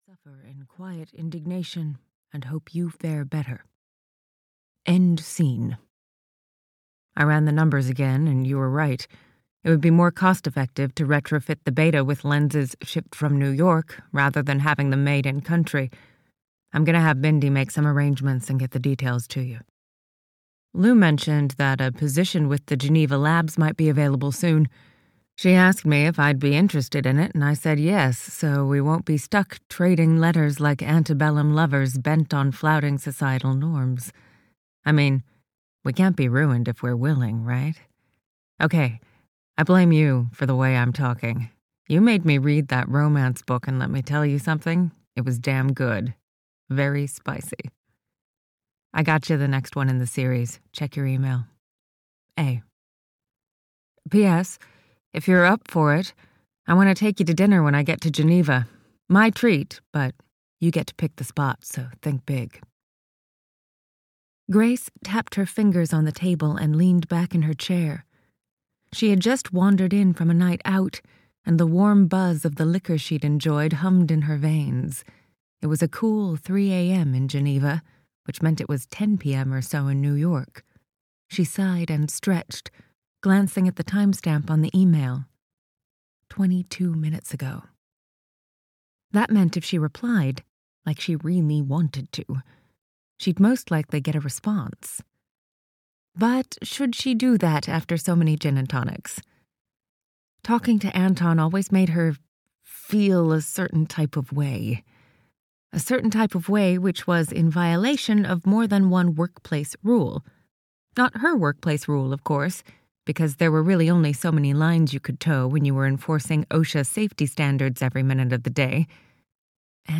Love and Gravity (EN) audiokniha
Ukázka z knihy